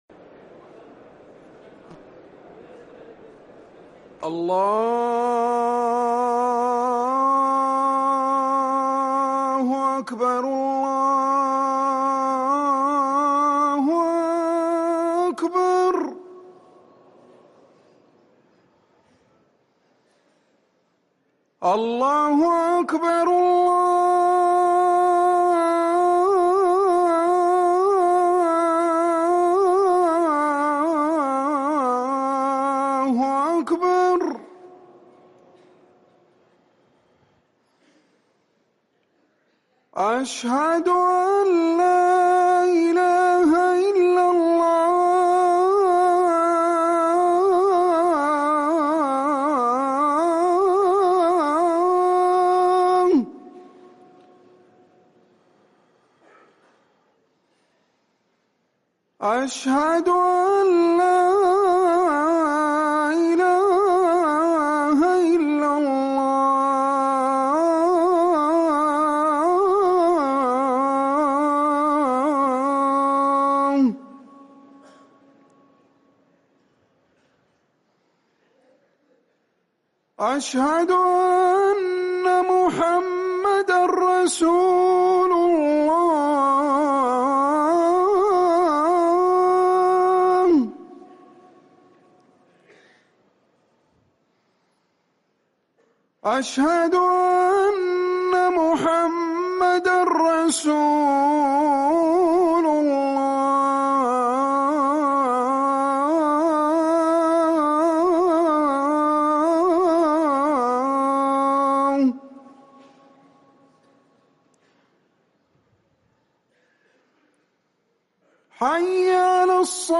اذان الظهر للمؤذن عمر سنبل الاربعاء 5 محرم 1444هـ > ١٤٤٤ 🕌 > ركن الأذان 🕌 > المزيد - تلاوات الحرمين